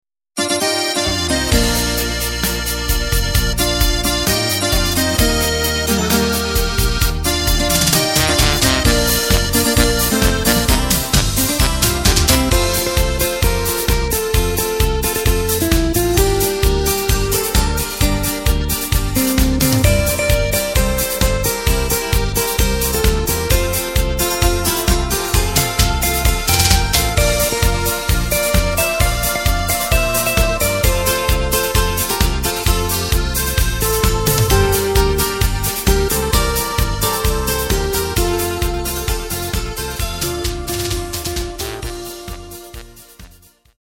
Takt:          4/4
Tempo:         131.00
Tonart:            Bb
Schlager-Polka aus dem Jahr aus dem Jahr 2018!